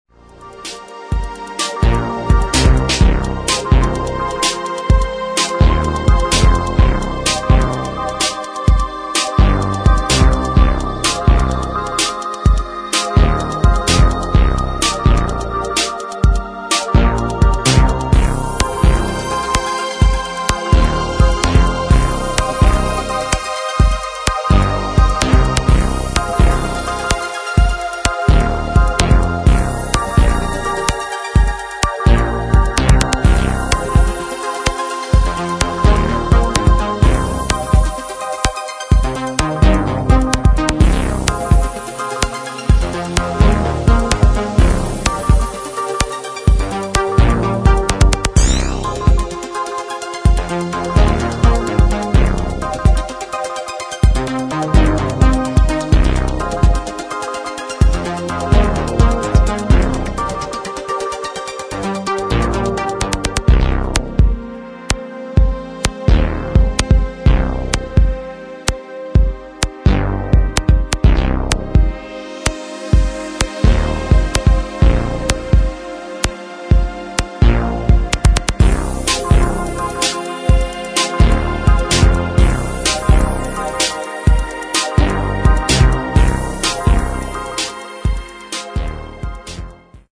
[ ELECTRO ]